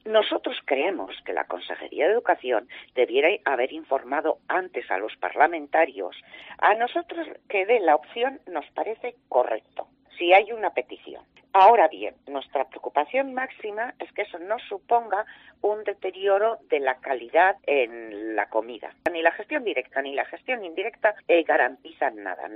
Juana Bengoetxea, parlamentaria del PP sobre comedores escolares